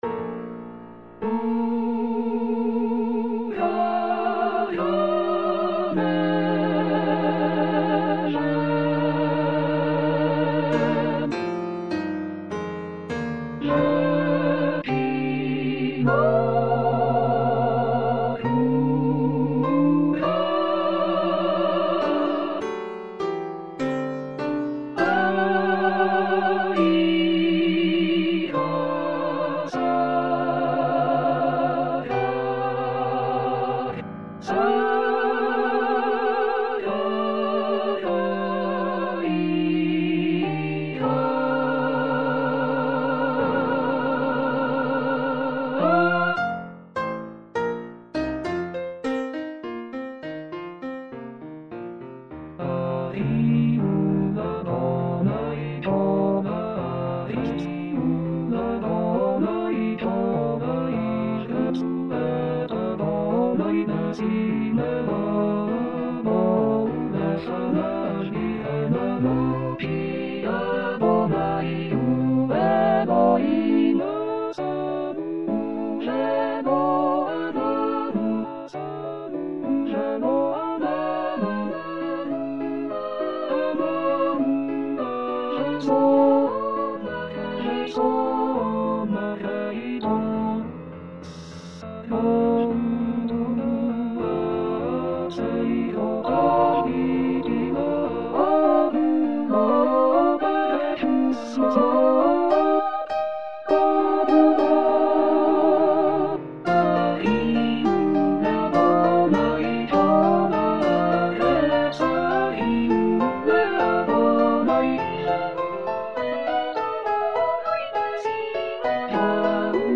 Toutes les voix